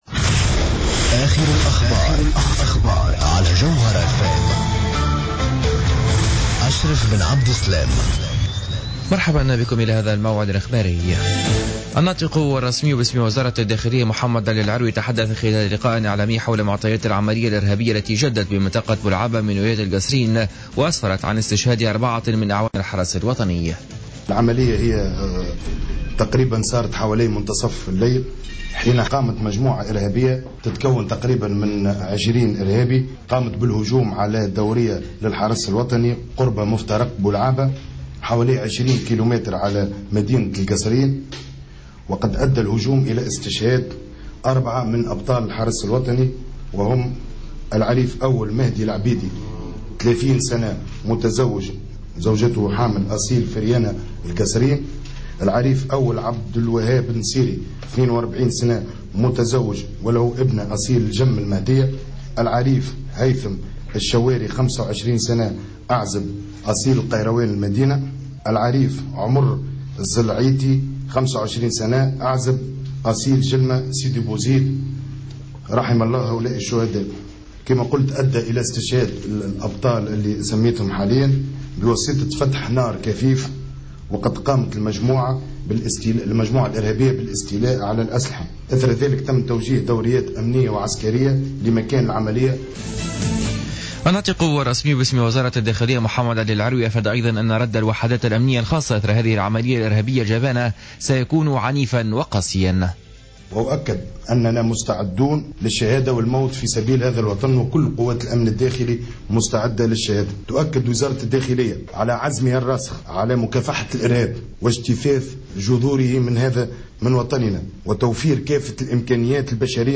نشرة أخبار منتصف الليل ليوم الخميس 19 فيفري 2015